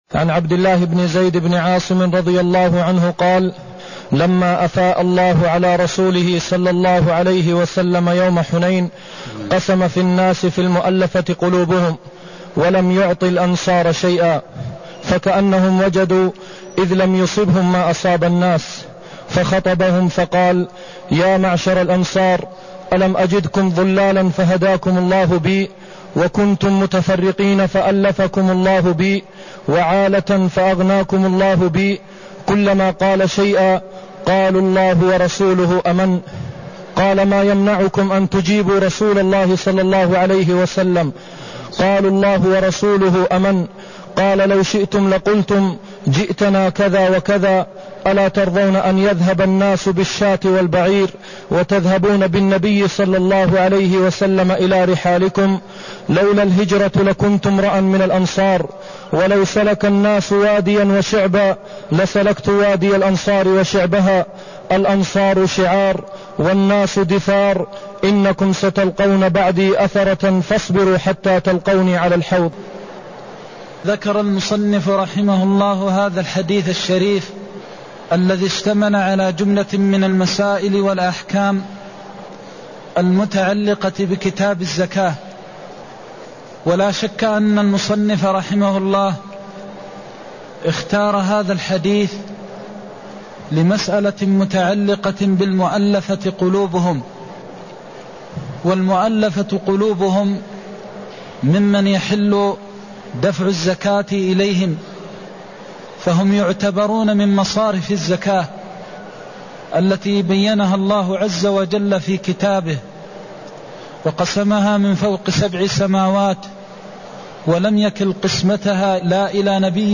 المكان: المسجد النبوي الشيخ: فضيلة الشيخ د. محمد بن محمد المختار فضيلة الشيخ د. محمد بن محمد المختار ألم أجدكم ضلالا فهداكم الله بي (167)